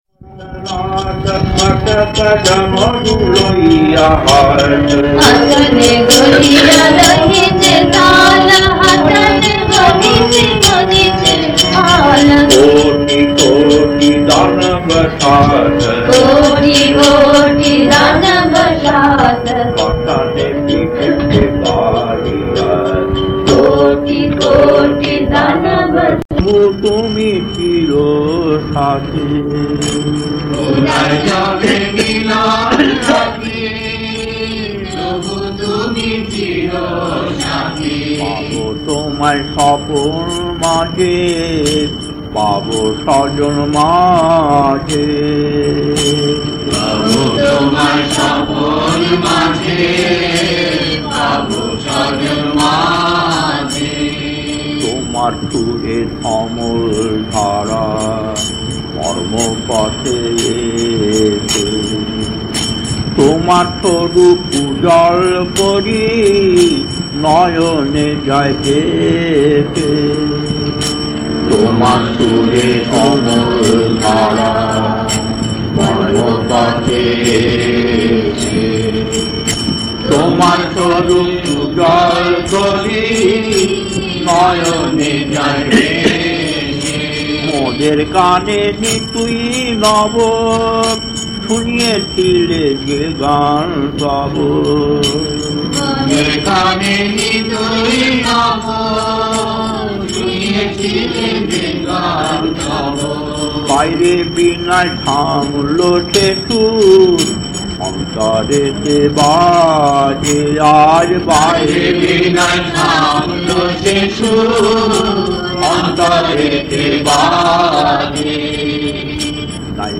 Kirtan A9-2 Alhambra mid 80's 1.